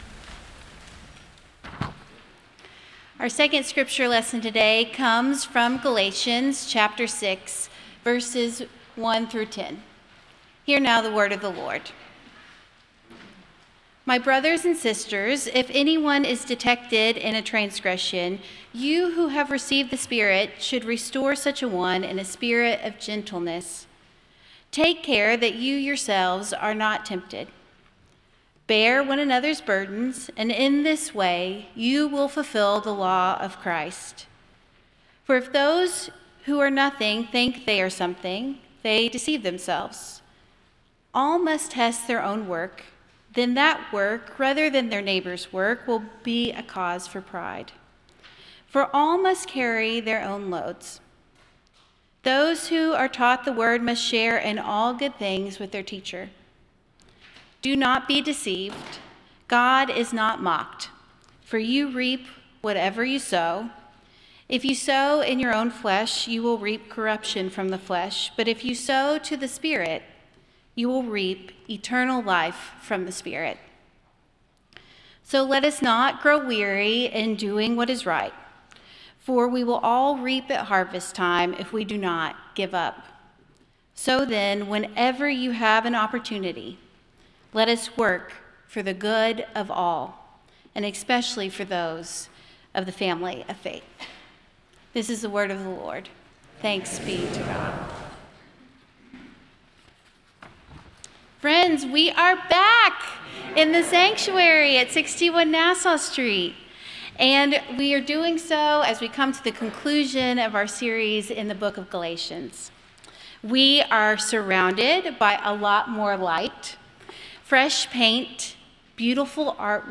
We are back in the sanctuary at 61 Nassau Street as we come to the conclusion of a series in Galatians!